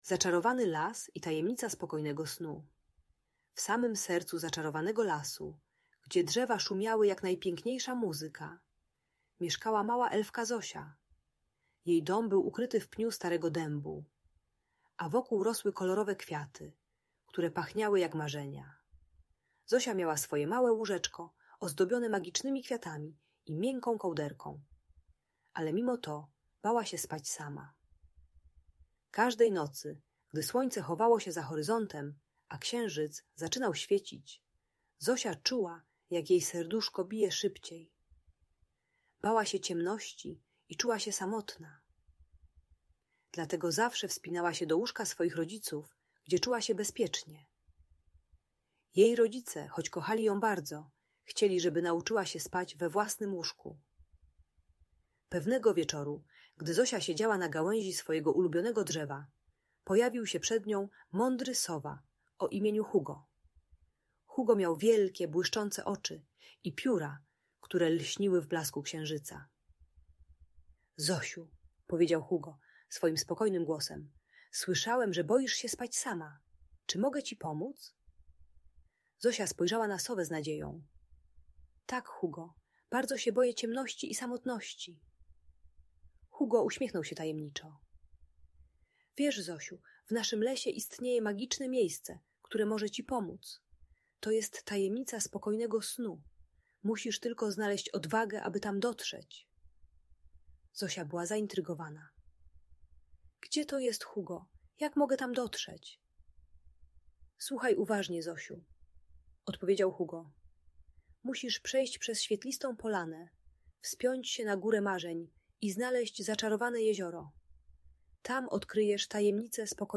Zaczarowany Las i Tajemnica Spokojnego Snu - Audiobajka